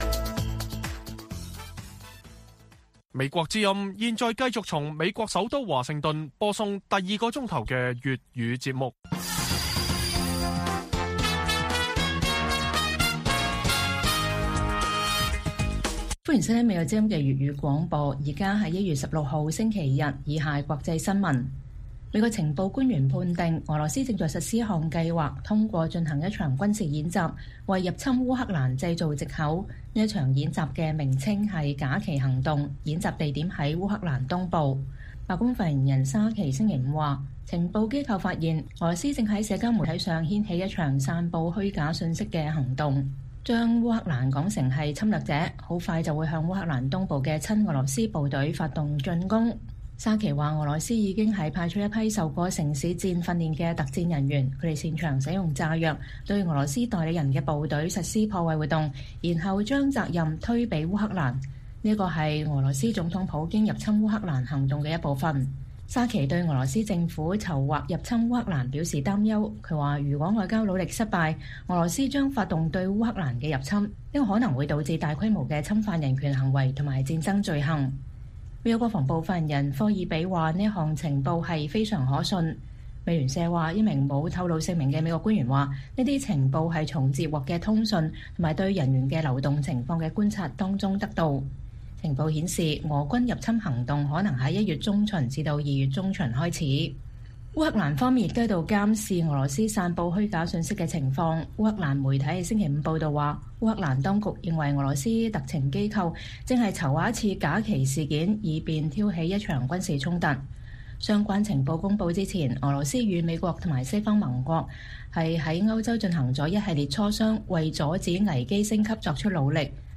粵語新聞 晚上10-11點：美情報發現 俄羅斯啟動輿論戰為入侵烏克蘭製造藉口